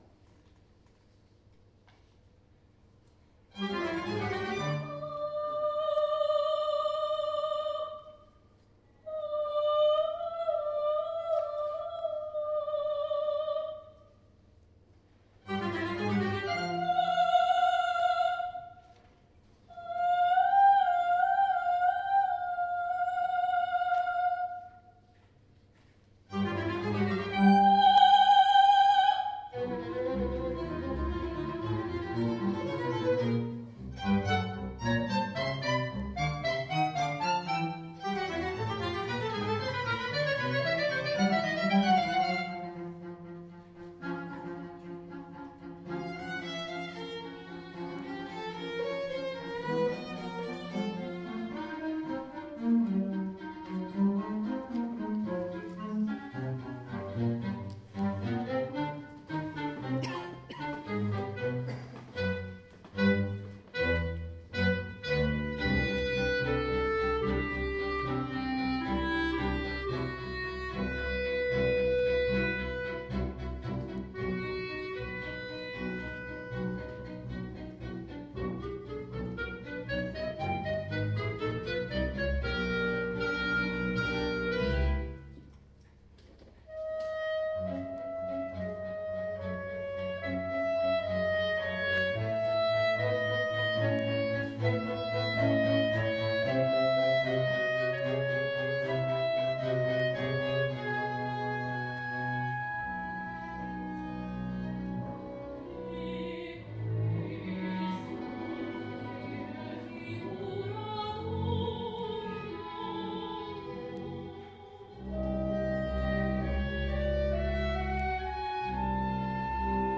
File audio del Concerto, di compositori e interpreti del Conservatorio Bonporti, nell’ambito del Festival di poesia Anterem a Verona
Il concerto si è tenuto domenica 24 ottobre alla Biblioteca Civica di Verona, alle ore 11.00.
Juan Zuleta (1984), Privazione con figura per mezzosoprano, clarinetto, violino, contrabbasso – testo di Antonio Prete